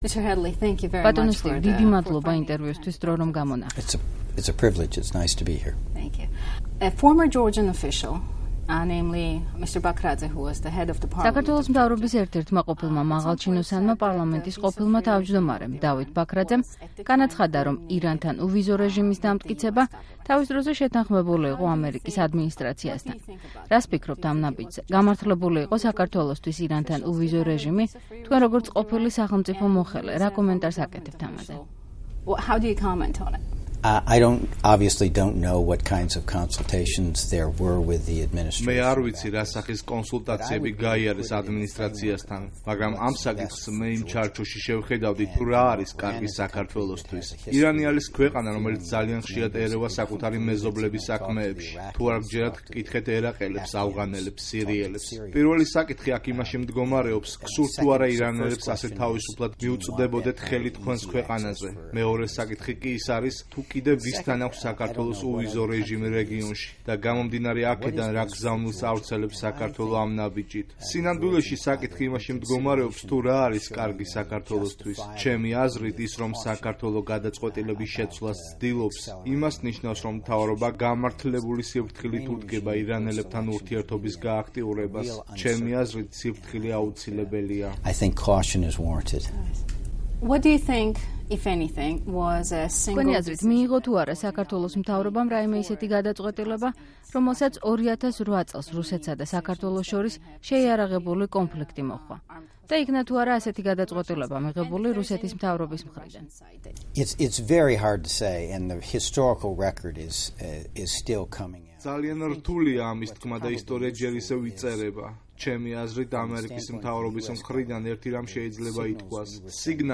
ინტერვიუ სტივ ჰედლისთან